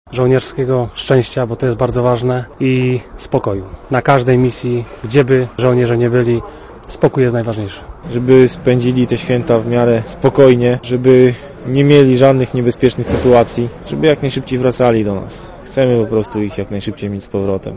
Komandosi z 18 batalionu desantowo-szturmowego z Bielska Białej, którzy służyli w poprzedniej zmianie polskiego kontyngentu, za pośrednictwem Radia Zet życzą kolegom spokoju i żołnierskiego szczęścia oraz szczęśliwego powrotu do domu.
Komentarz audio